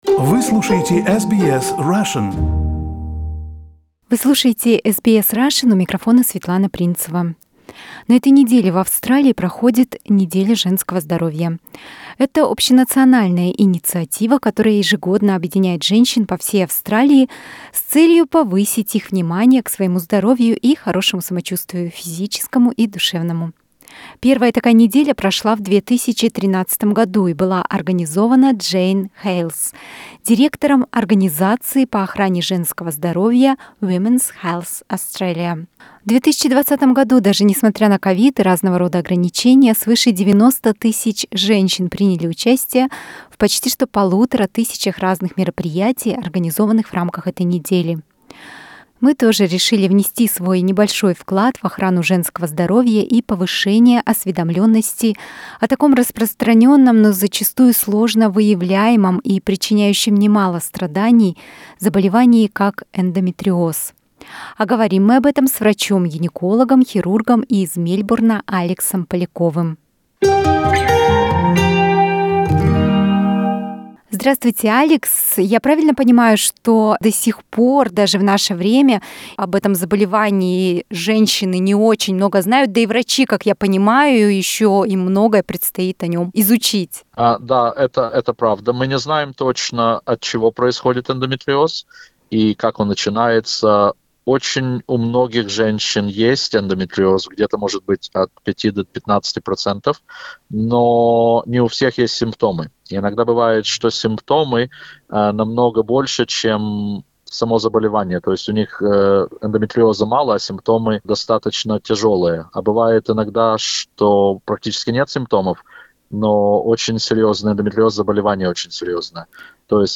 В рамках цикла бесед о женском здоровье